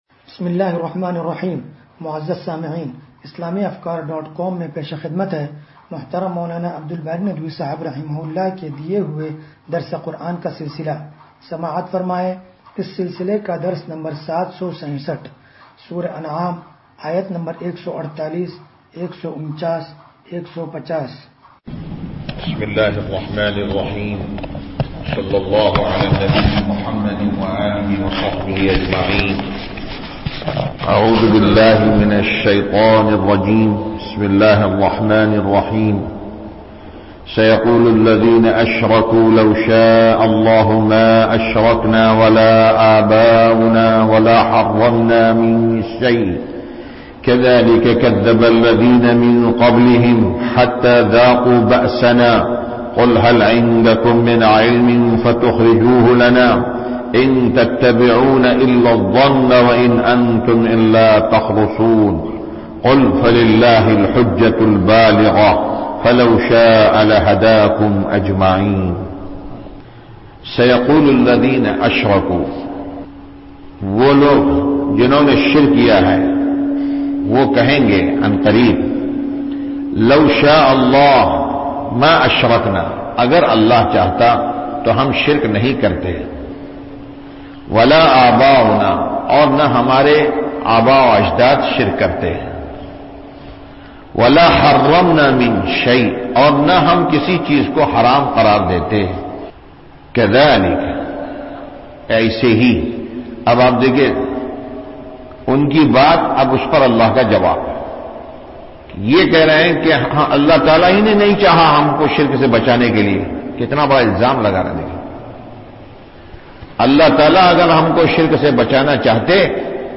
درس قرآن نمبر 0767